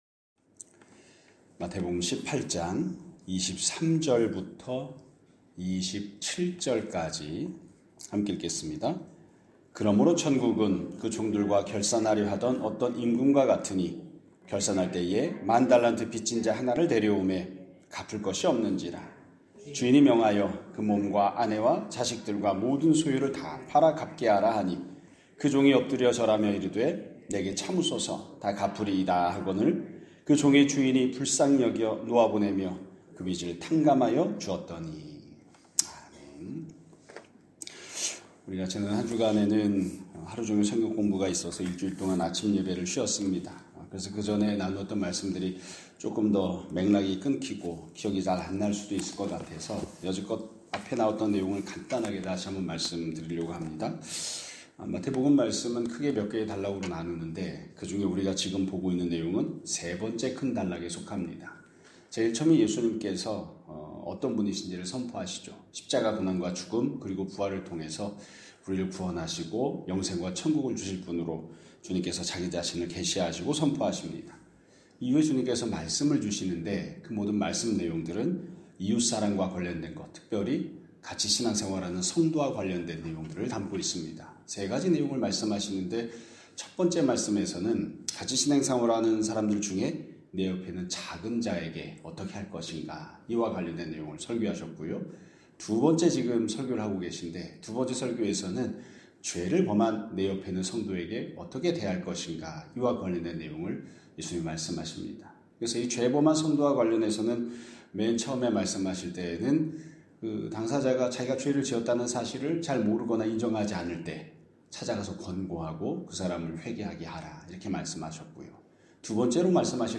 2025년 12월 22일 (월요일) <아침예배> 설교입니다.